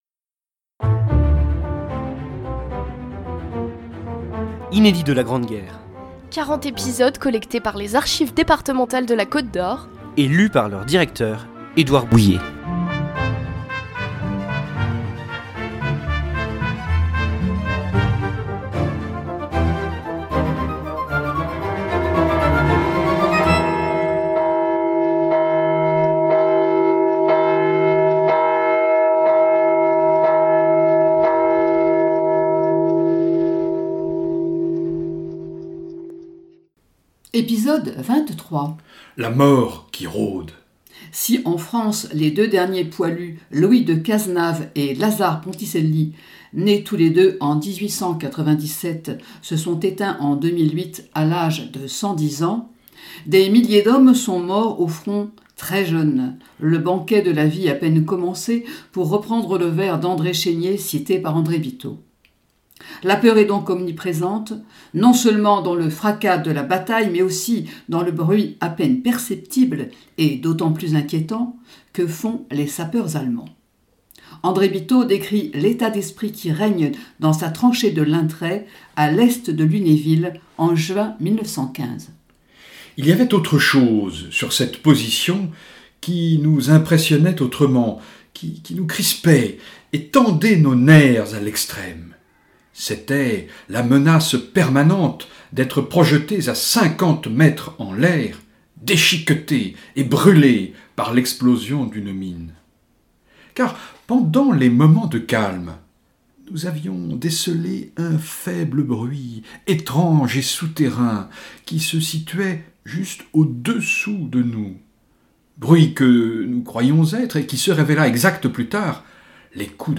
Un feuilleton de lettres et de journaux intimes à suivre jusqu’au 11 novembre 2018, pour célébrer le centenaire de l’armistice de la Grande Guerre, signé à Rethondes le 11 novembre 1918 !